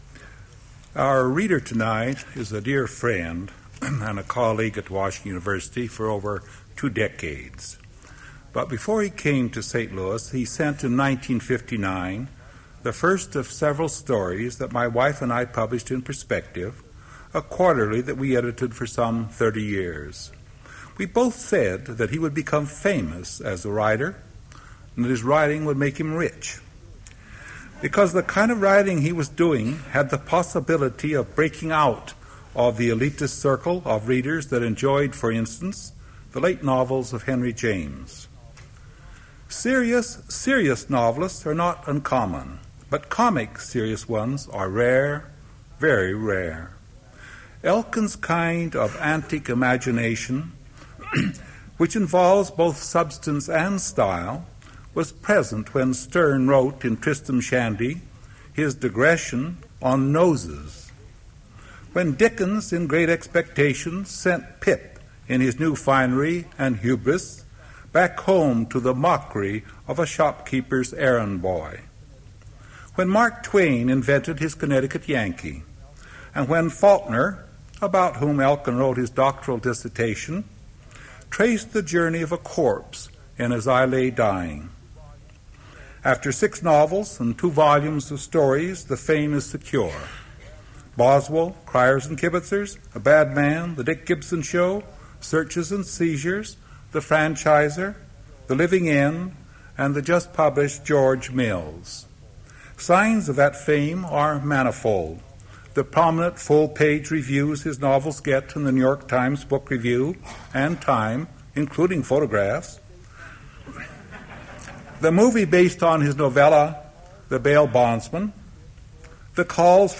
Prose reading featuring Stanley Elkin
Attributes Attribute Name Values Description Stanley Elkin reading from his novel, George Mills. Part of the River Styx PM recordings.
Source mp3 edited access file was created from unedited access file which was sourced from preservation WAV file that was generated from original audio cassette.
Reading seems to be incomplete